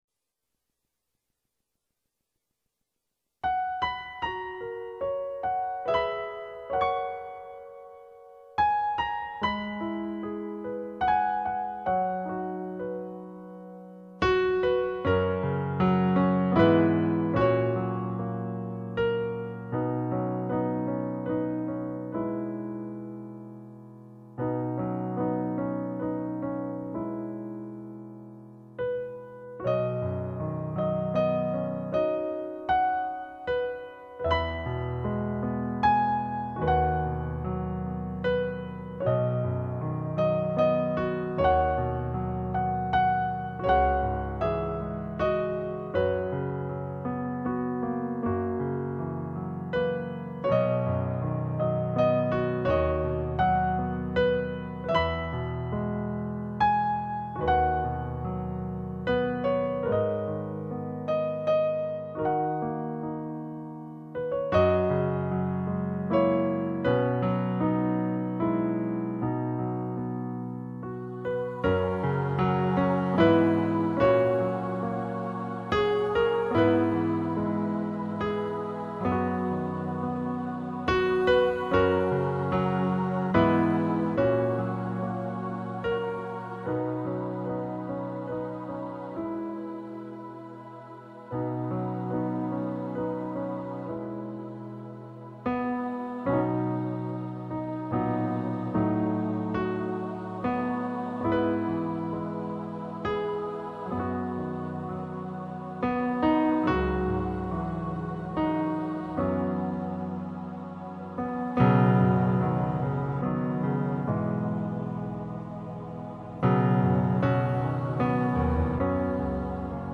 from Faith Baptist Church